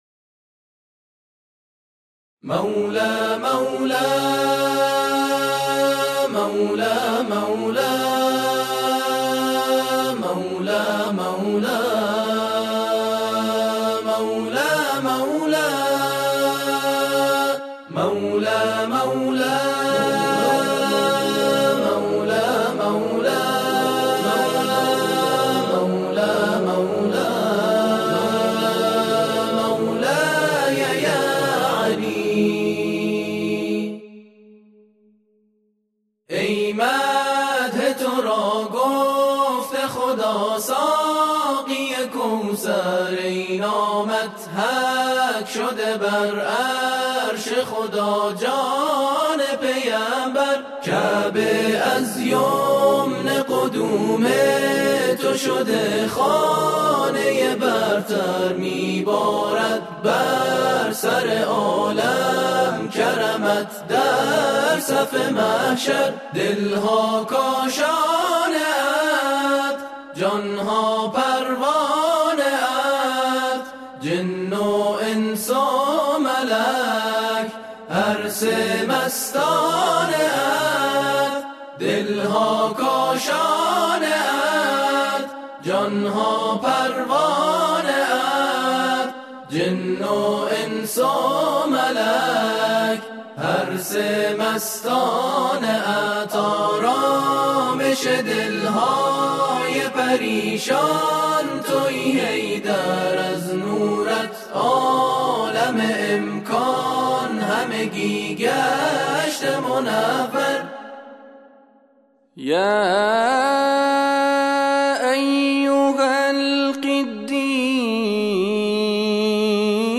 که در حریم امن ولی نعمتمان آقا امام رضا اجرا شده است